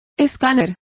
Complete with pronunciation of the translation of scanner.